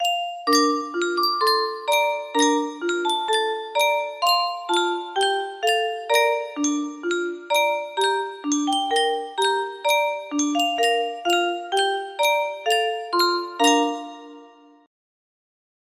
Yunsheng Music Box - America the Beautiful Y340
Full range 60